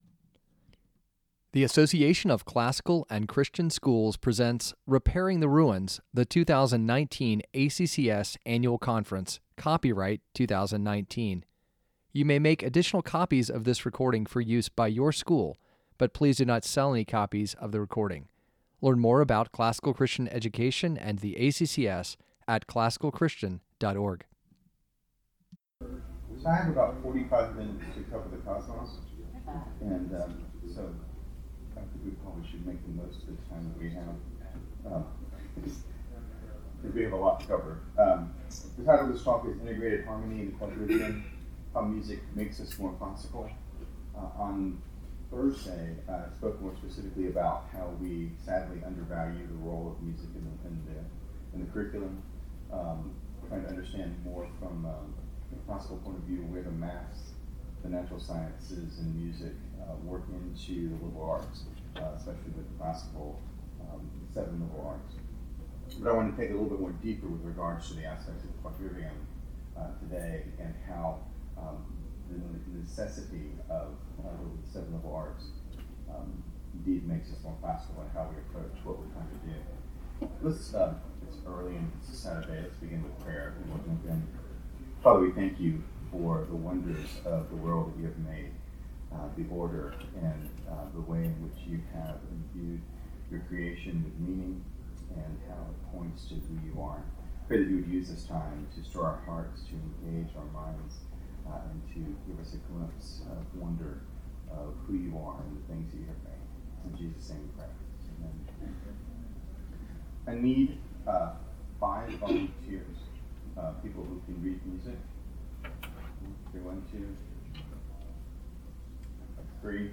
2019 Workshop Talk | 48:55 | 7-12, Art & Music, Math, Quadrivium